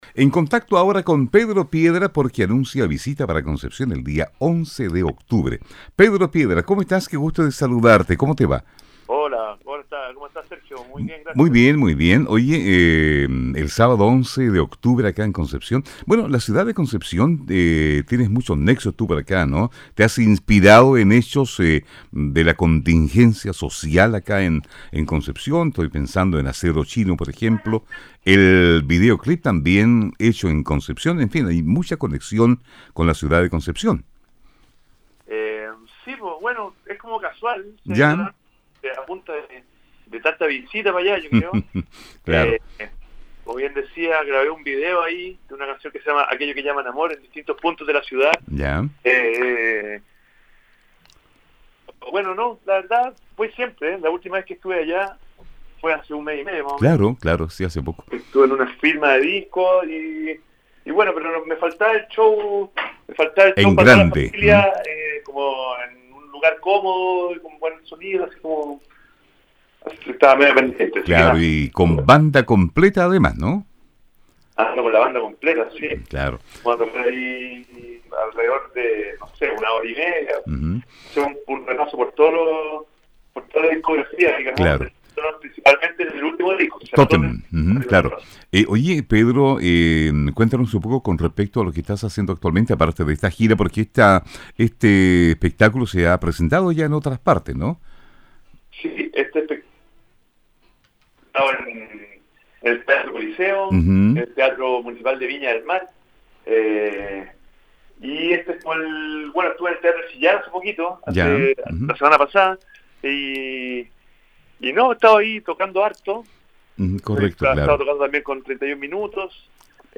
Entrevista-Pedro-Piedra.mp3